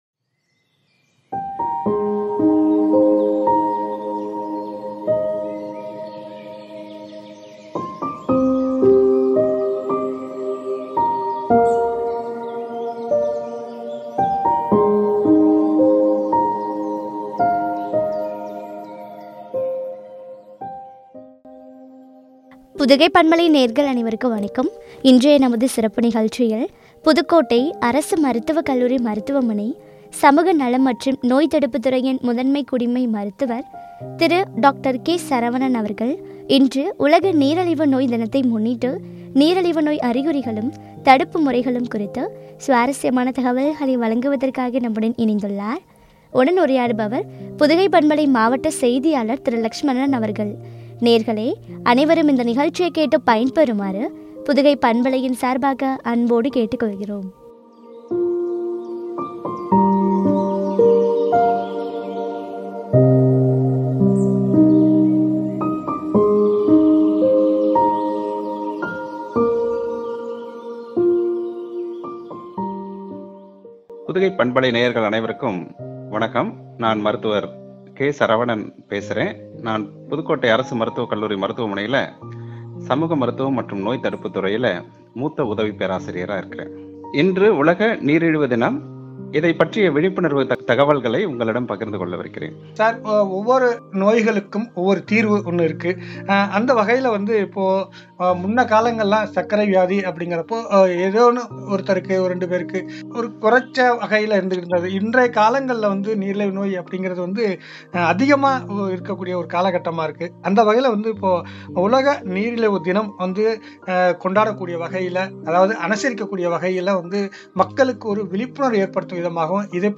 தடுப்பு முறைகளும் குறித்து உரையாடல்.